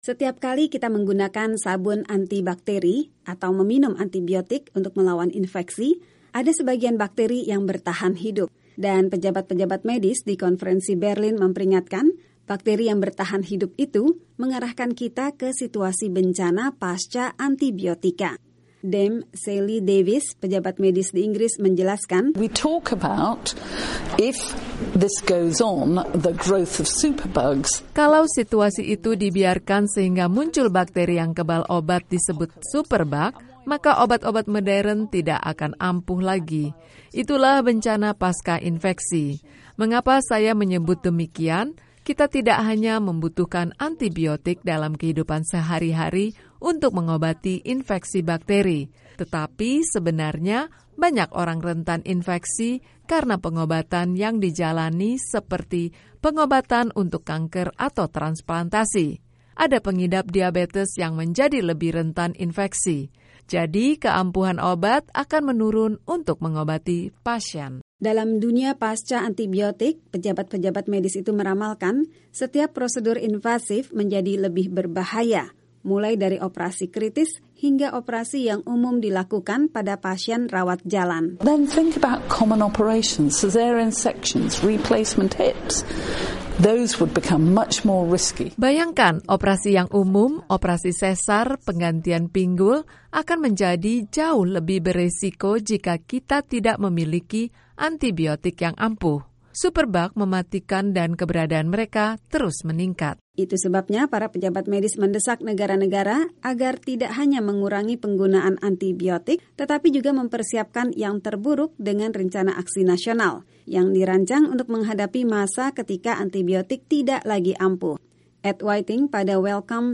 menyampaikan laporan ini.